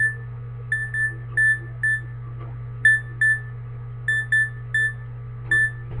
描述：听起来像摩斯密码
Tag: 高频 MORS码 环路 哔哔